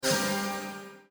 notify.mp3